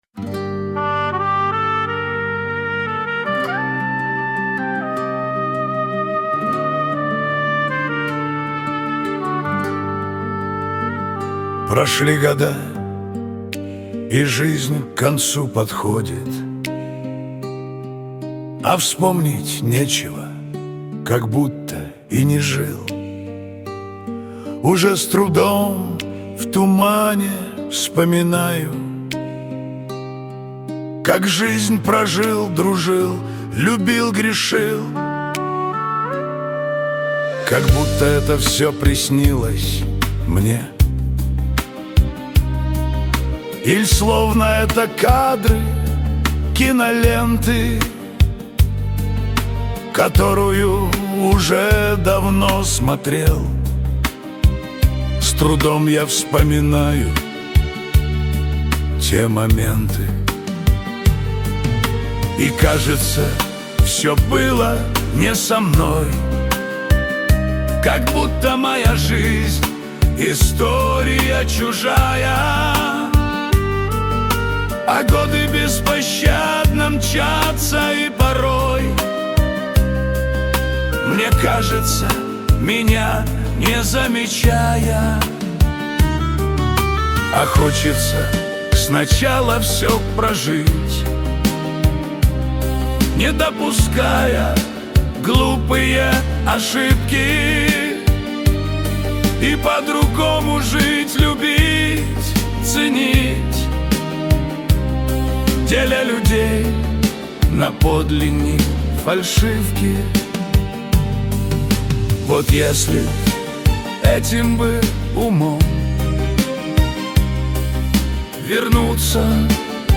shanson_dlja_dushi_proshli_goda.mp3